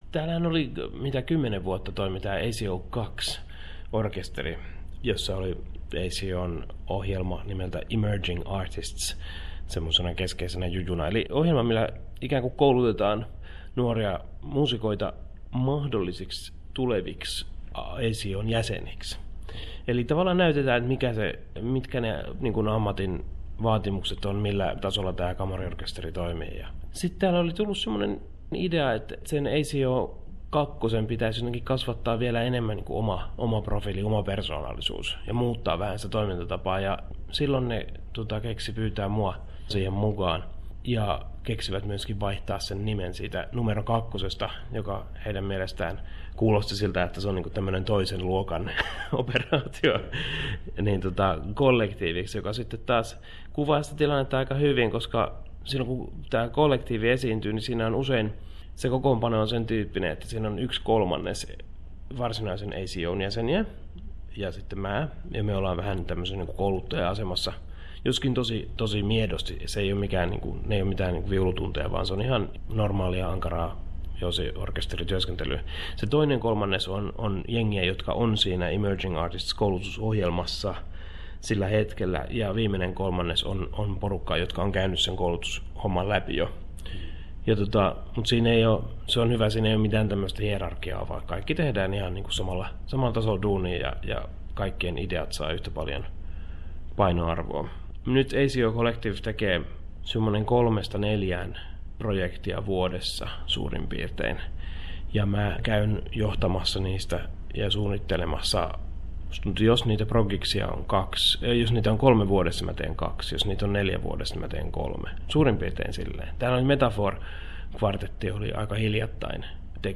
Pekka Kuusisto kertoo jännittävästä esityksestä.